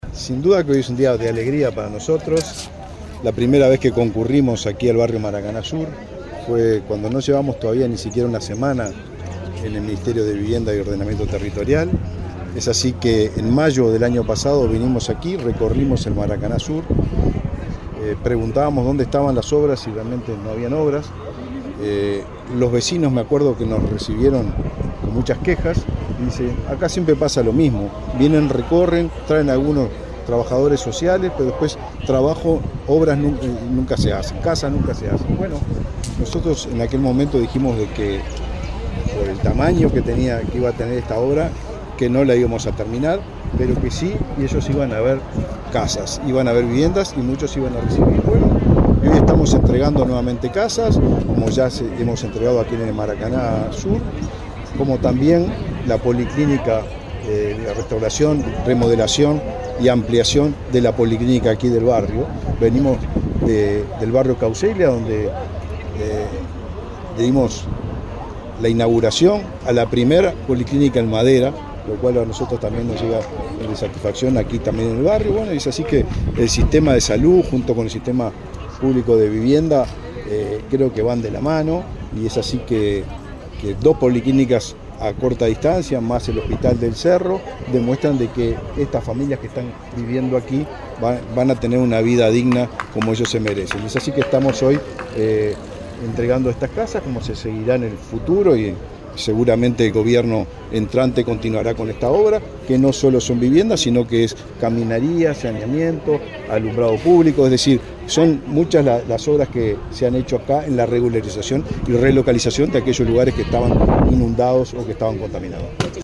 Declaraciones del ministro de Vivienda, Raúl Lozano
El ministro de Vivienda, Raúl Lozano, dialogó con la prensa, luego de encabezar un acto de inauguración de viviendas en el barrio Maracaná, en